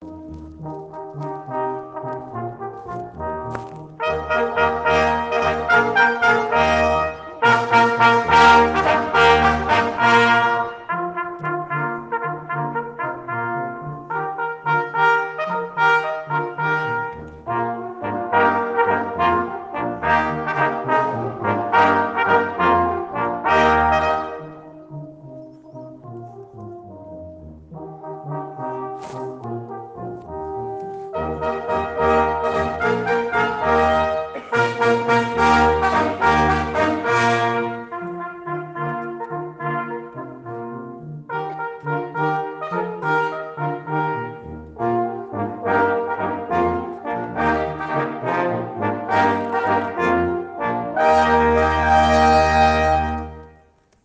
Le mardi 7 janvier 2020 après-midi, toutes les classes du cycle 2 sont allées à la Maison des Arts et de la Culture d’Épinay-sous-Sénart pour voir et écouter l’Orchestre de la Police Nationale (section cuivres).
Sur scène, il y avait 7 musiciens qui jouaient des instruments suivants :
-un cor
– deux trompettes de cavalerie
– une trompette basse
– un clairon
– un clairon basse
– un tuba